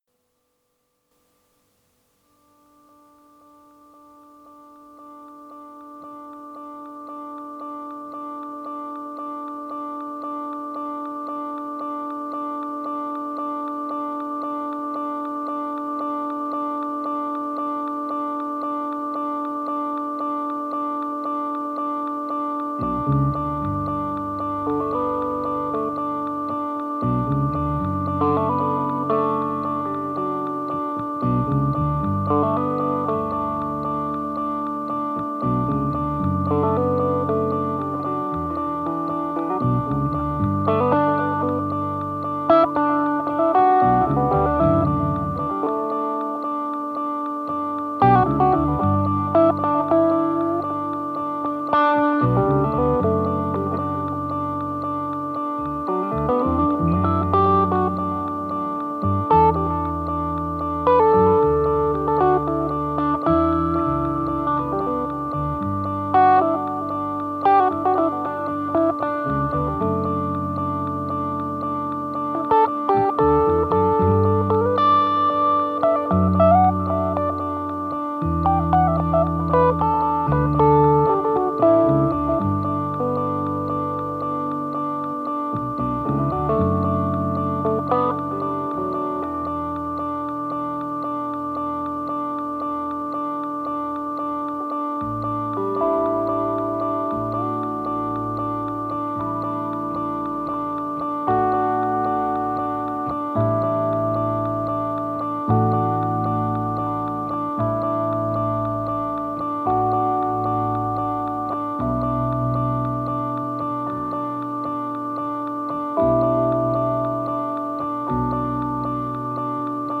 impro-on-a-loop-bug.mp3